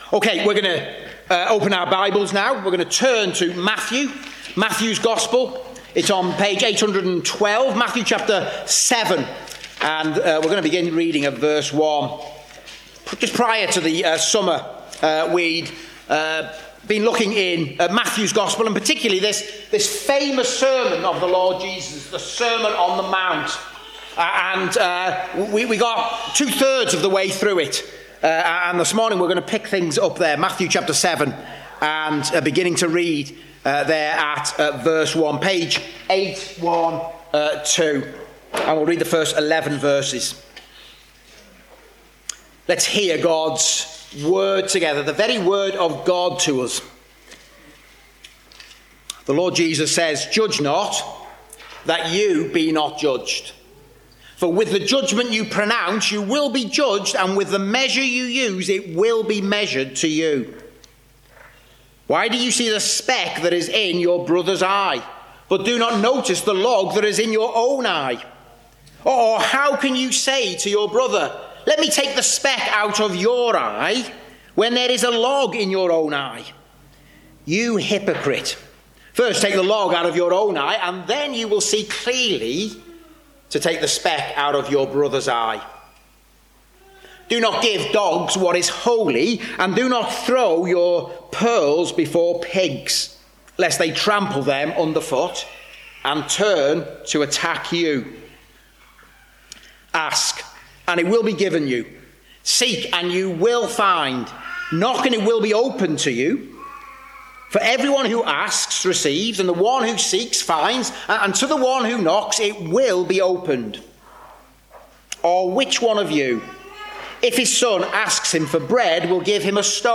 Matthew 7:1-11 Service Type: Preaching Jesus calls members of God’s Kingdom to see clearly.